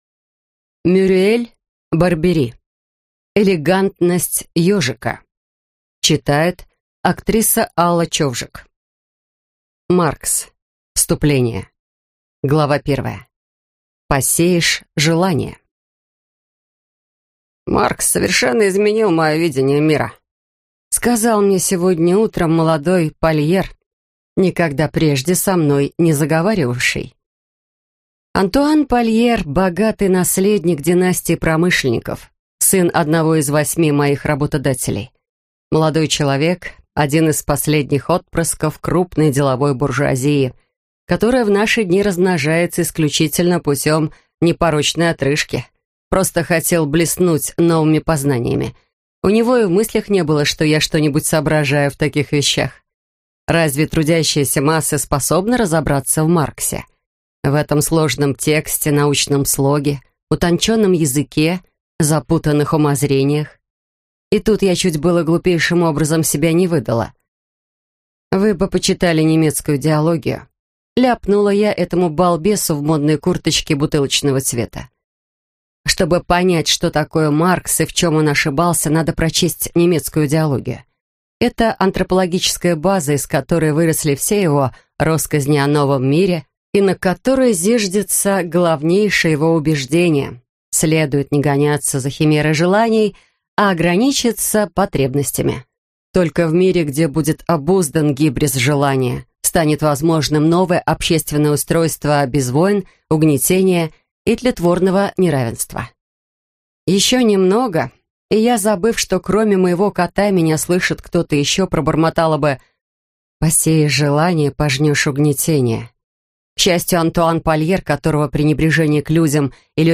Аудиокнига Элегантность ёжика | Библиотека аудиокниг